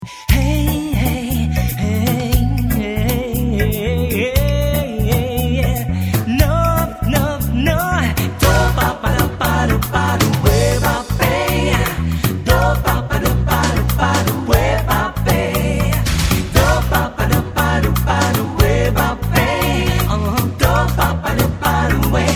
Chant
Basse
Guitares
Claviers